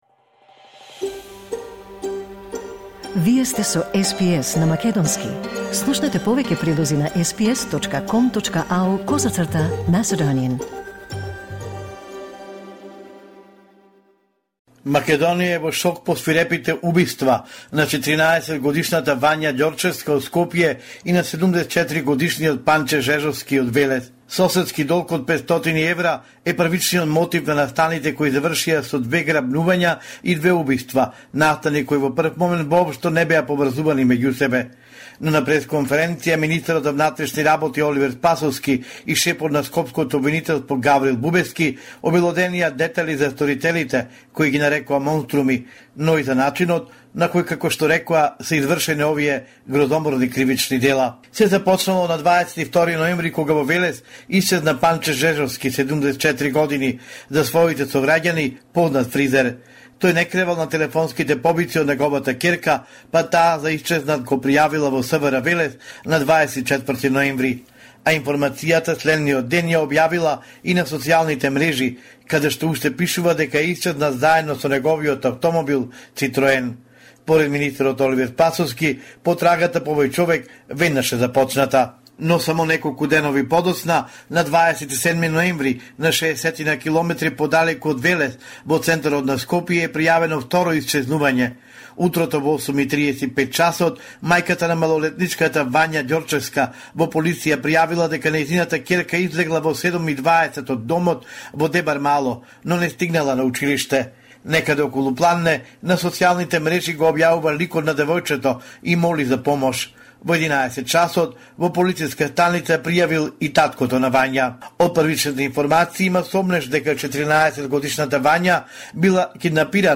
Homeland Report in Macedonian 5 December 2023